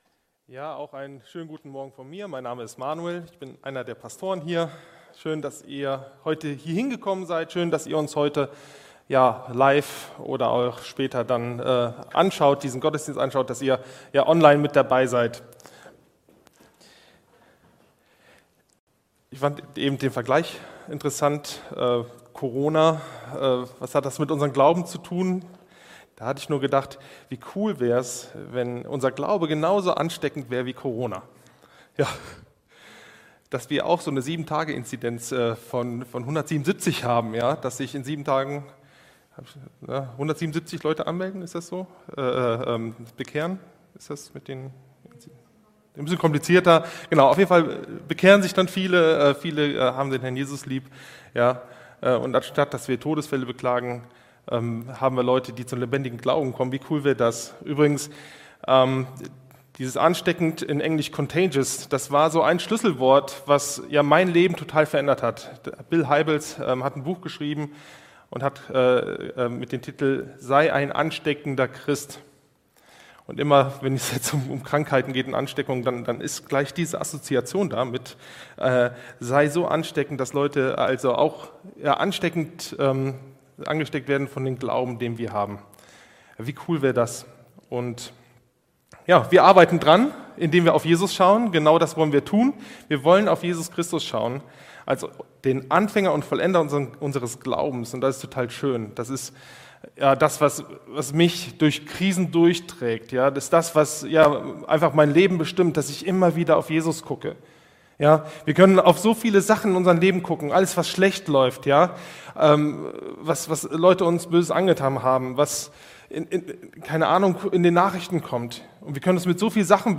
„Jesus und: …“ | Gottesdienst